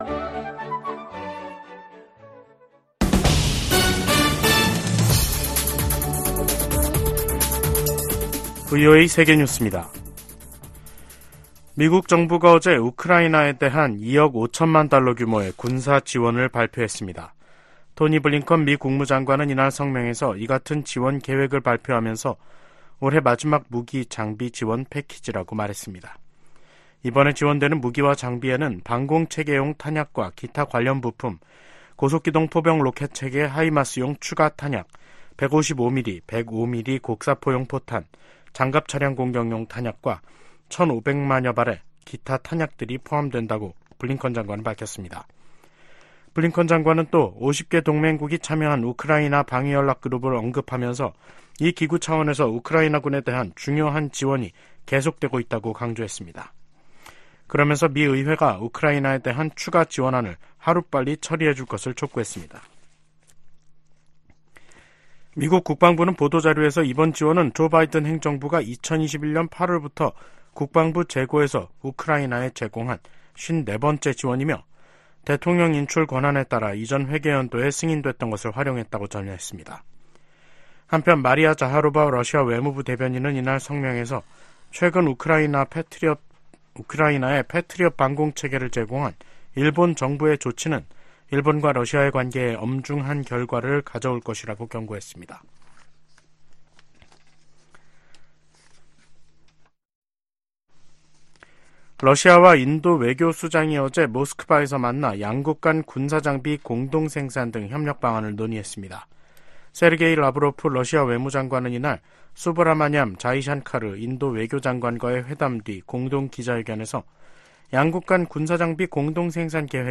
VOA 한국어 간판 뉴스 프로그램 '뉴스 투데이', 2023년 12월 28일 2부 방송입니다. 김정은 북한 국무위원장이 당 전원회의서 전쟁 준비에 박차를 가하는 전투과업을 제시했습니다. 미 국무부가 북한의 중요 정치행사에 등장한 벤츠 행렬에 대해 대북 제재의 운송수단 반입 금지 의무를 상기시켰습니다. 전 주한미군사령관들이 신년 메시지를 통해 미한 연합훈련과 가치 동맹의 확대를 주문했습니다.